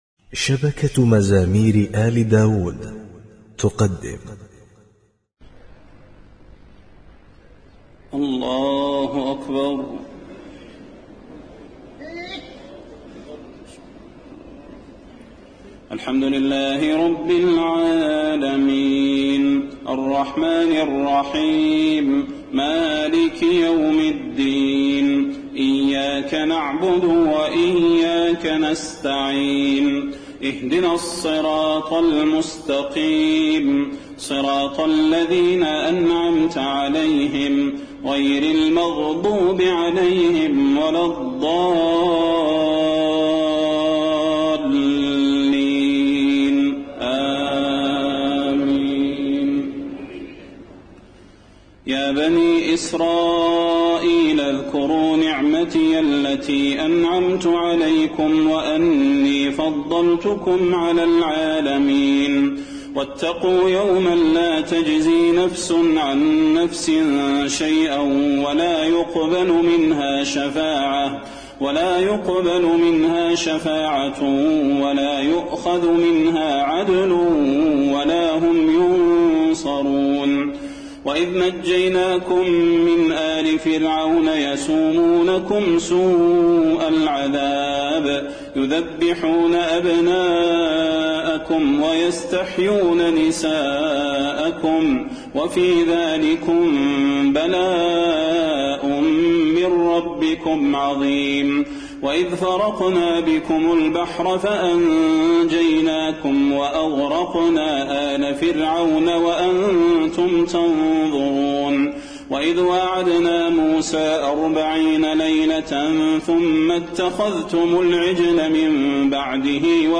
تهجد ليلة 21 رمضان 1432هـ من سورة البقرة (47-105) Tahajjud 21 st night Ramadan 1432H from Surah Al-Baqara > تراويح الحرم النبوي عام 1432 🕌 > التراويح - تلاوات الحرمين